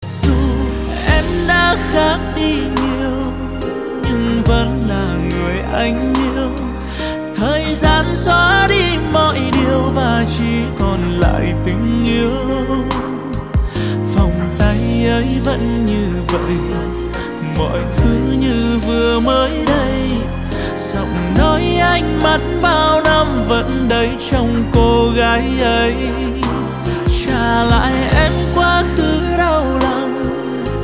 Âm điệu nhẹ nhàng, dễ nghe